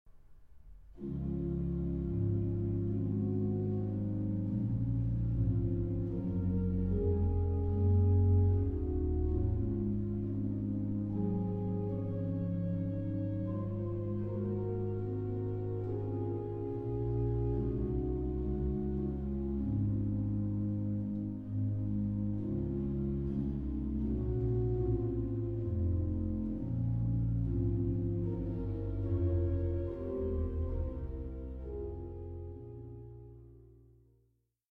in Middelburg, The Netherlands